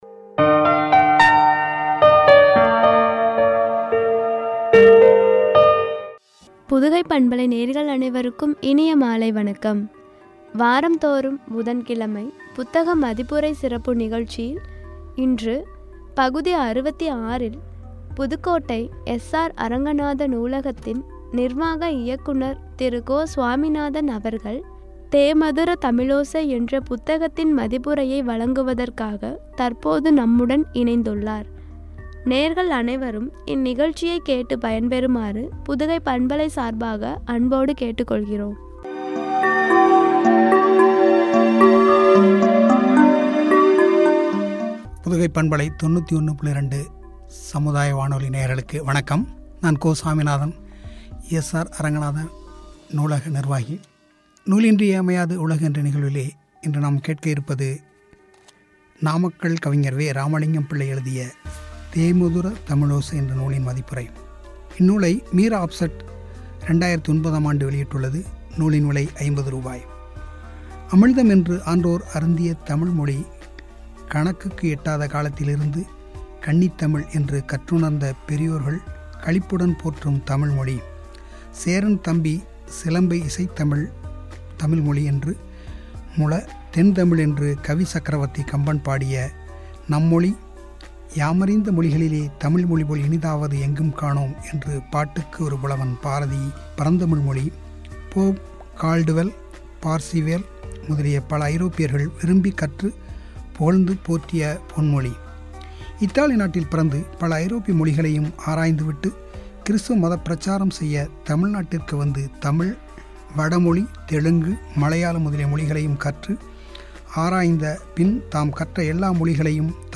“தேமதுரத் தமிழோசை” புத்தக மதிப்புரை (பகுதி – 66), குறித்து வழங்கிய உரை.